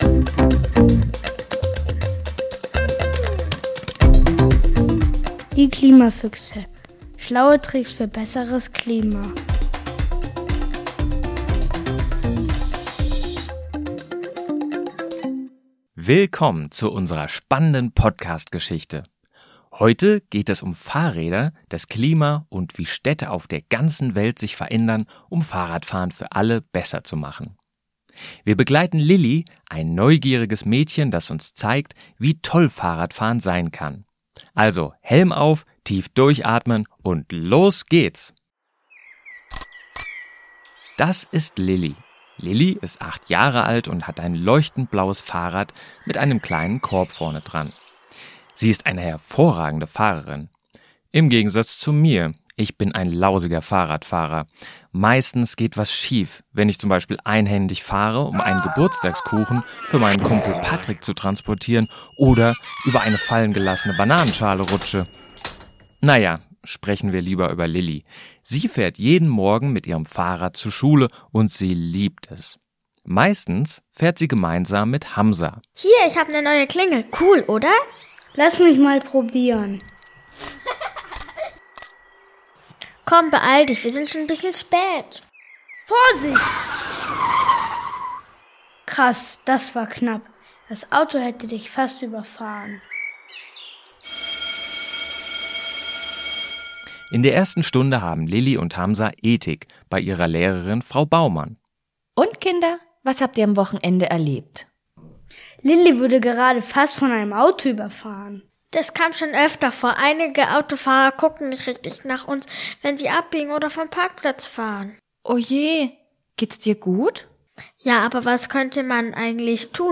Ein kleines Hörspiel zu fahrradfreundlichen Städten